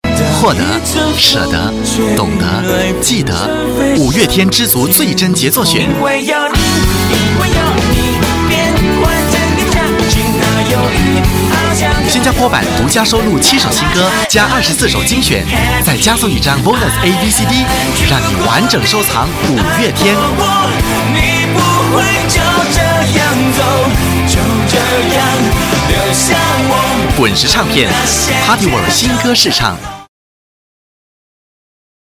Voice Samples: Mandarin Voice Sample 05
male